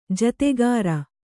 ♪ jategāri